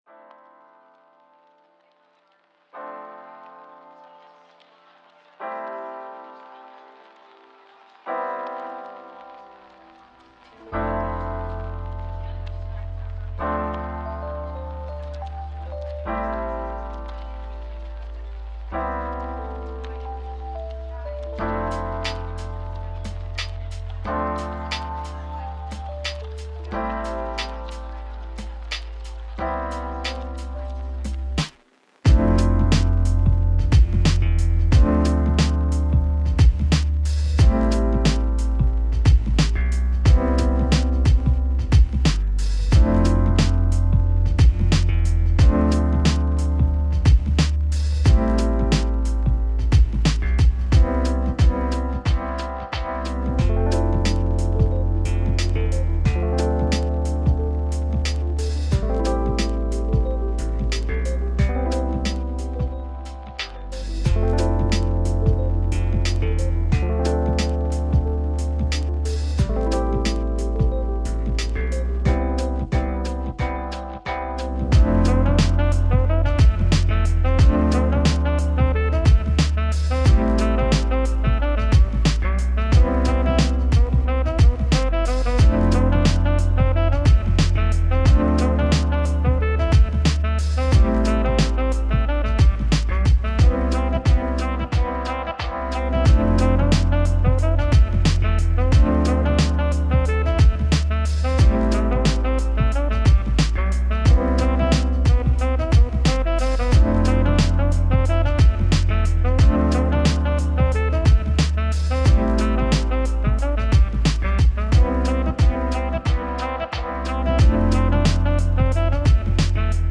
Лоуфай музыка с вибрацией не для всех но самая крутая